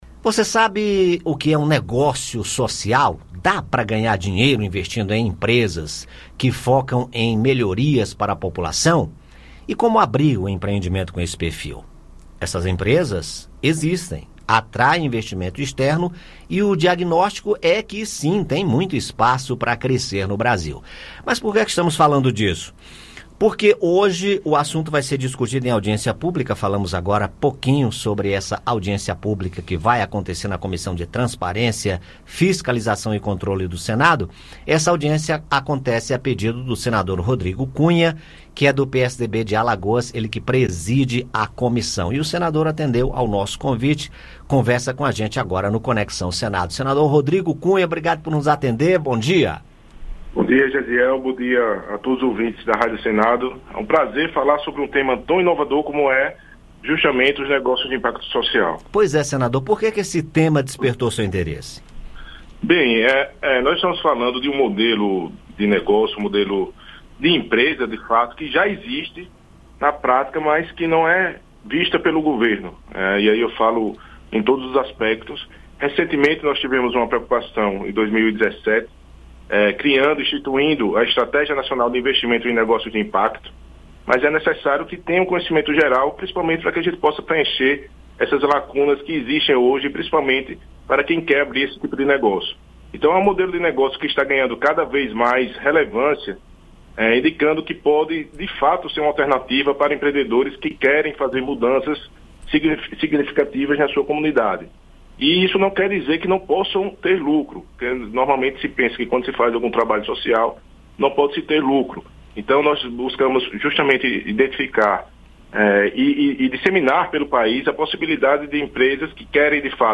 O senador conversou com a equipe do Conexão Senado sobre o assunto. Ouça o áudio com a entrevista.